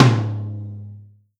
Tom 01.wav